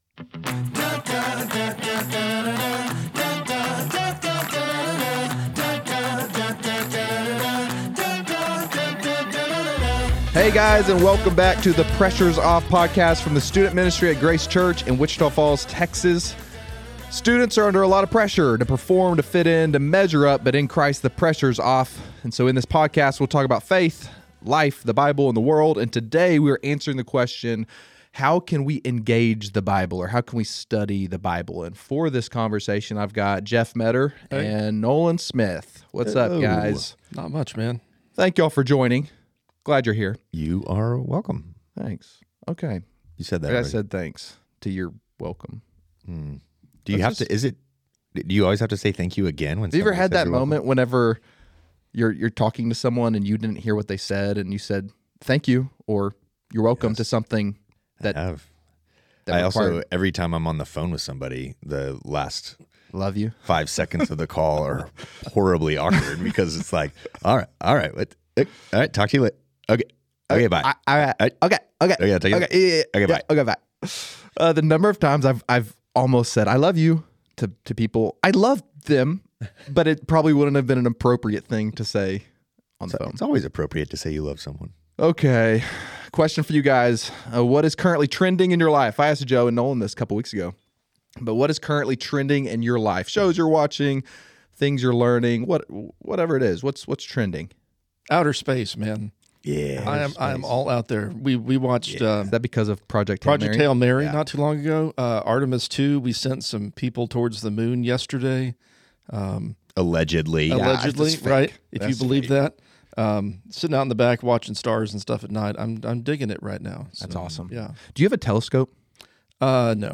Student leaders and guests sit down to discuss life, theology, and the Bible to help students know and follow Jesus at a deeper level.